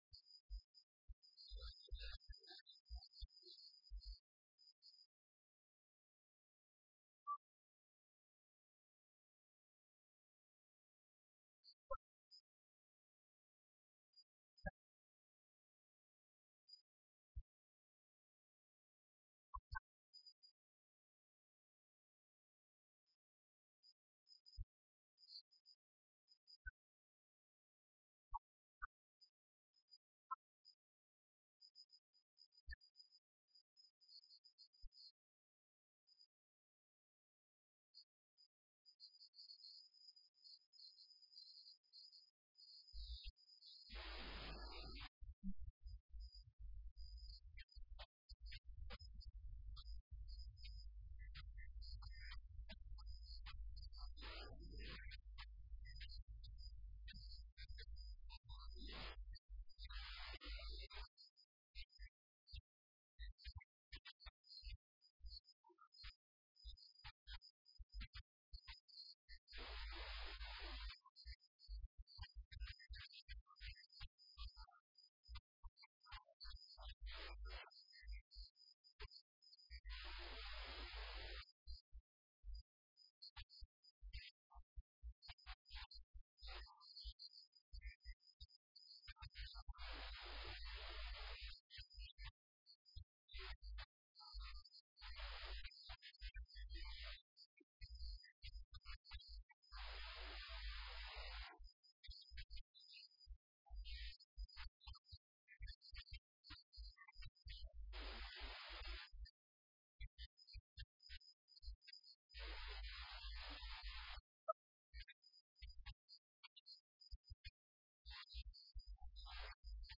Dhagaeyso Warka Subax ee Radio Muqdisho